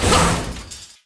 machine_die1.wav